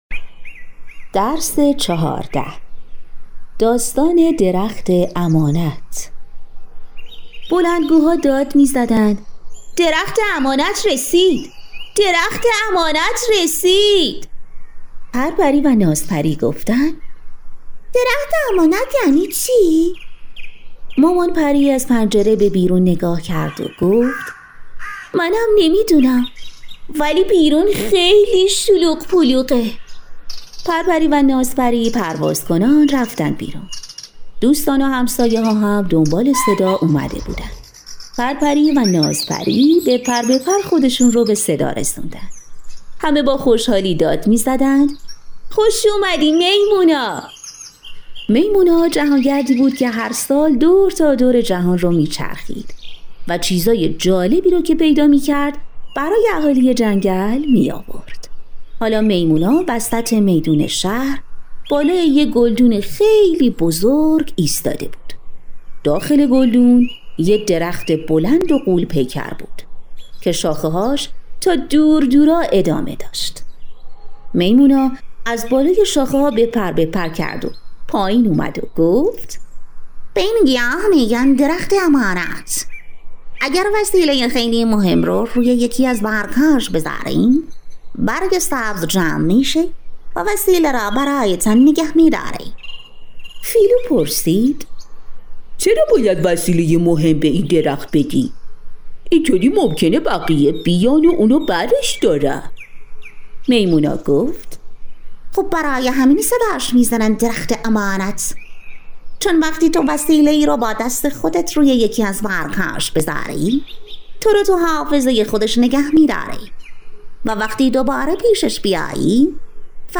فعالیت 2: داستان «درخت امانت» از نوآموزان بپرسید آیا می‌دانید امانت یعنی چی؟